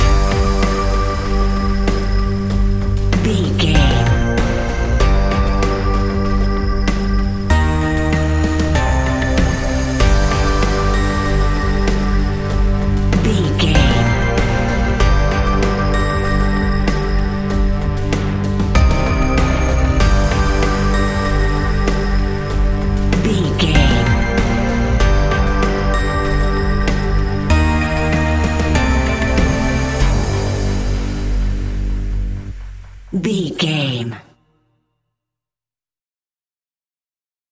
Aeolian/Minor
tension
ominous
dark
eerie
synthesiser
ticking
electronic music